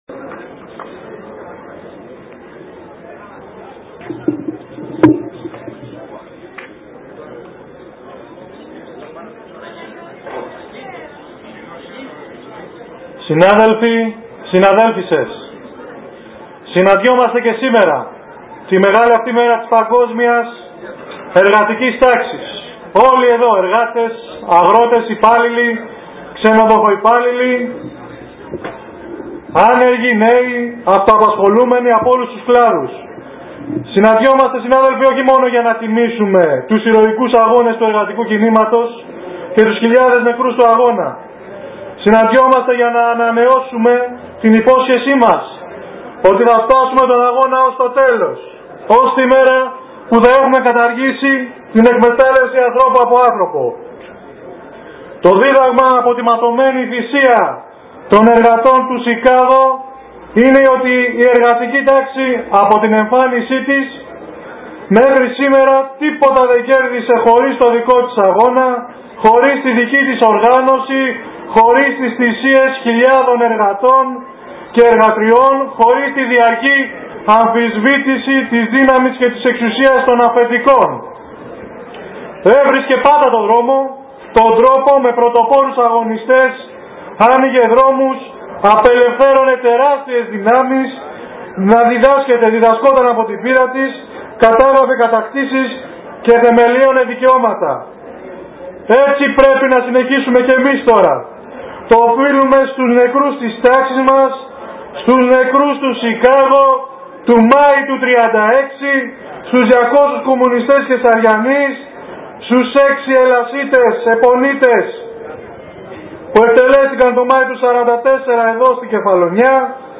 Γιορτάστηκε η πρωτομαγιά με συγκέντρωση στο ΕΚΚΙ και πορεία.
Εργατική Πρωτομαγιά σήμερα και πραγματοποιήθηκε συγκέντρωση στο Εργατικό Κέντρο με ομιλίες, πορεία στους δρόμους του Αργοστολίου και κατάθεση στεφανιών στο μνημείο των πεσόντων στην περιοχή του Ξενία.